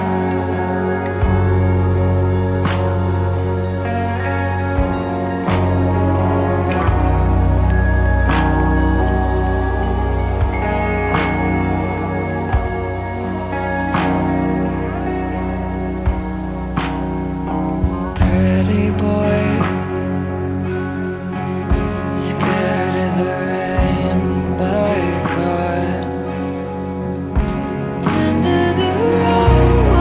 gótico, extremamente romântico e melancólico.